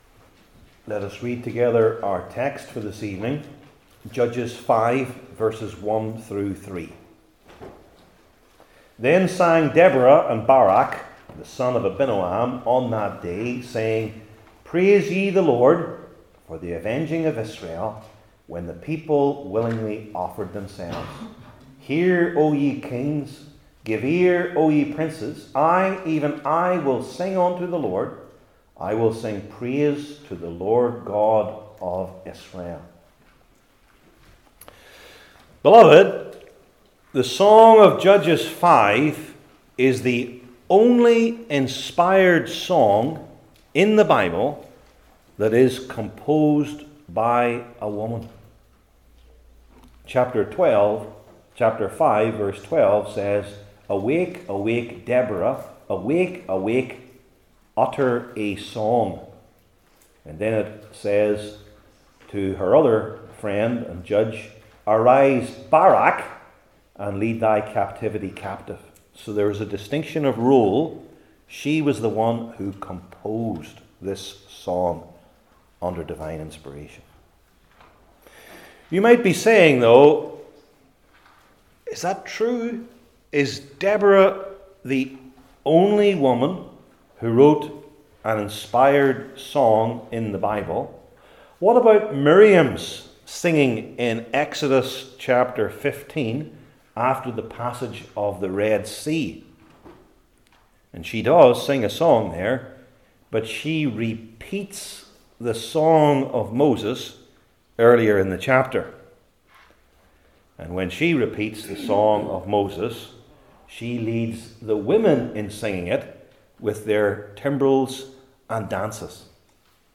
Old Testament Sermon Series I. The Striking Reason II.